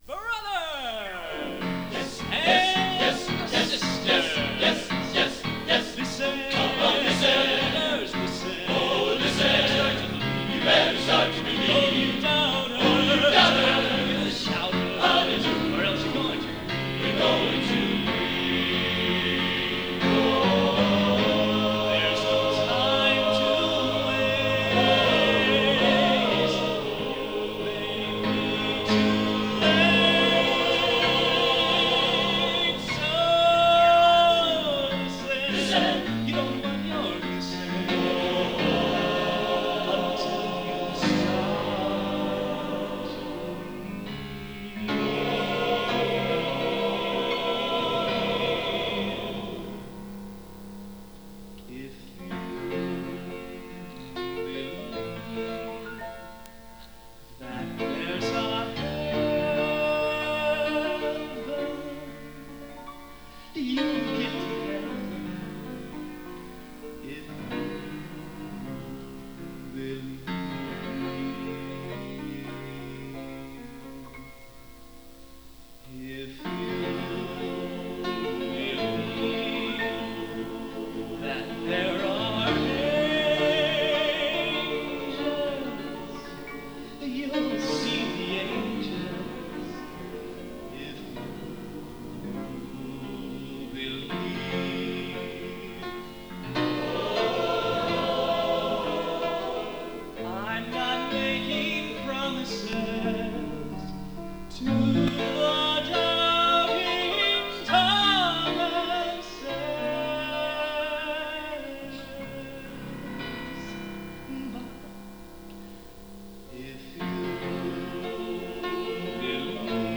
Genre: Broadway | Type: Solo